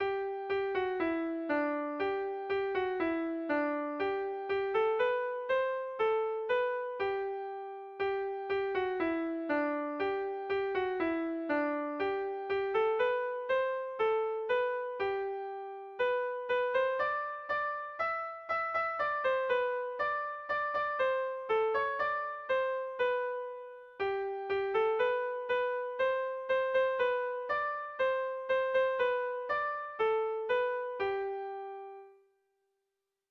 Bertso melodies - View details   To know more about this section
Lauko handia (hg) / Bi puntuko handia (ip)
AABD